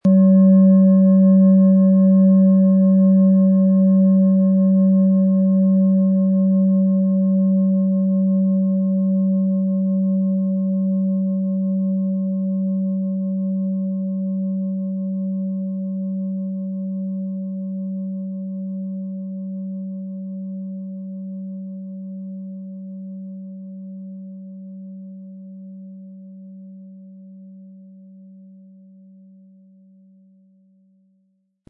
• Tiefster Ton: Mond
PlanetentönePluto & Mond
MaterialBronze